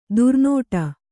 ♪ durnōṭa